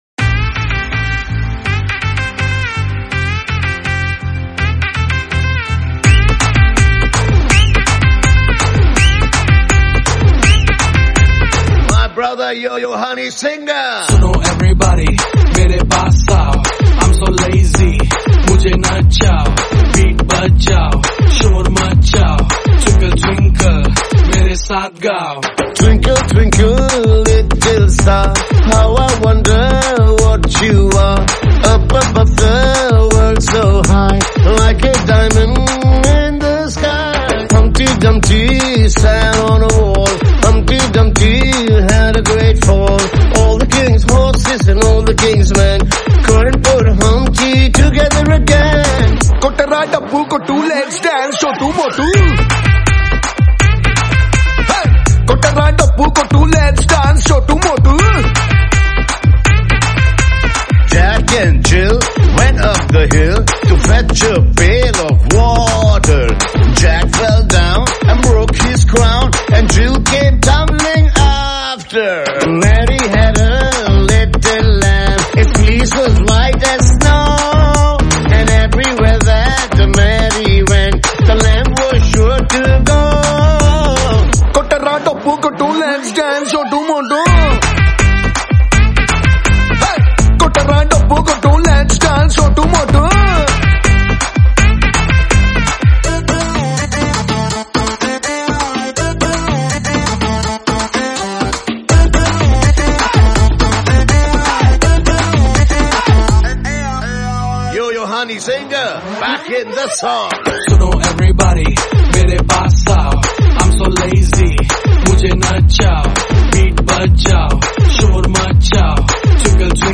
a medley of nursery rhymes